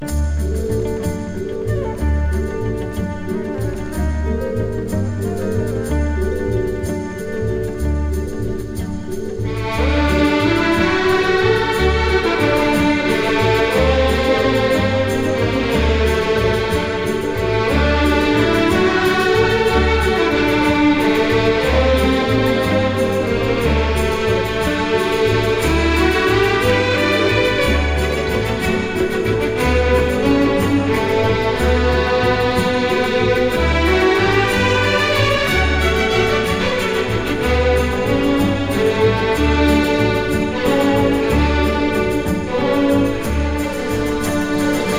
本盤で取り組んだのはキューバ音楽。